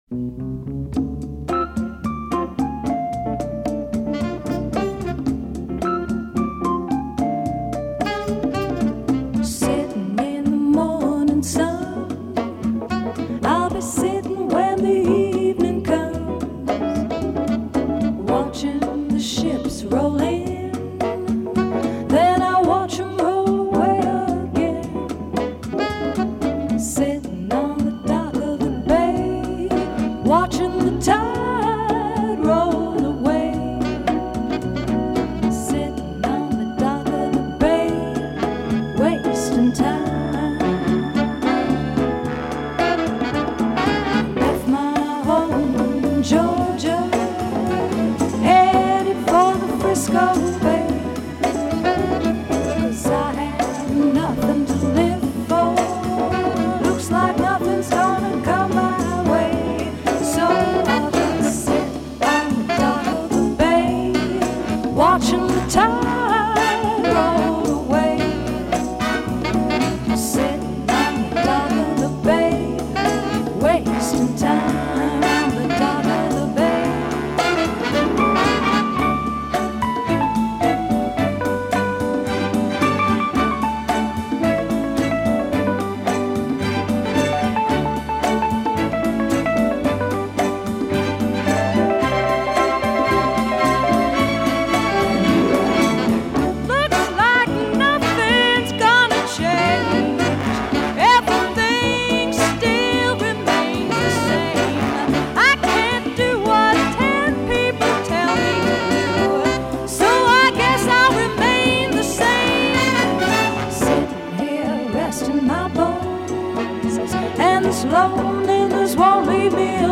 TEMPO : 103
Ce morceau n'a que des accords Majeurs !!
Pont instrumental (interressant) Jun 14